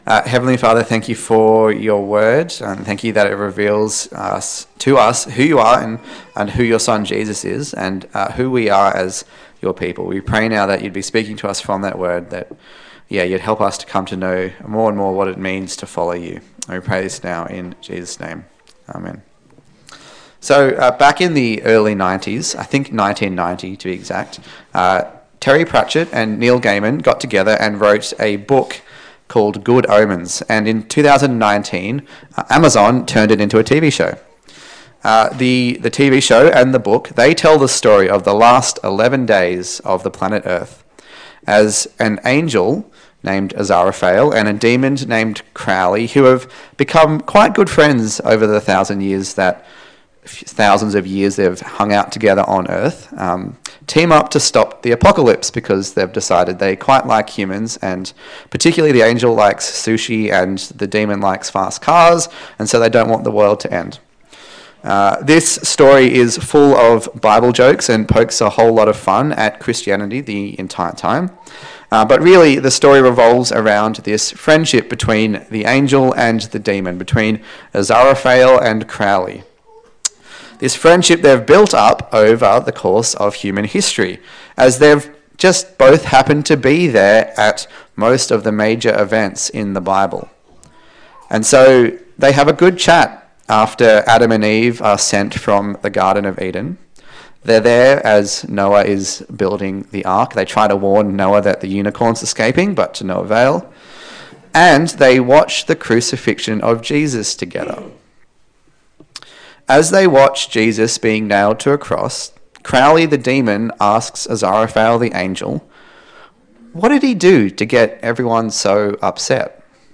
A sermon in the series on the book of Luke
Luke Passage: Luke 6:17-49 Service Type: Sunday Service